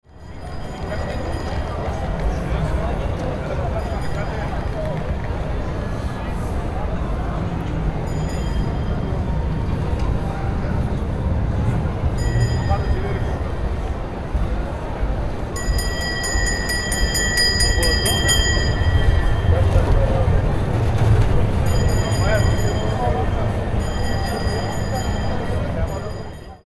Tram Arriving And Passing With Ringing Bell Sound Effect
Description: Tram arriving and passing with ringing bell sound effect. Urban background ambiance with deep rumble of tram wagons, people talking, and distant city traffic noise.
Genres: Sound Effects
Tram-arriving-and-passing-with-ringing-bell-sound-effect.mp3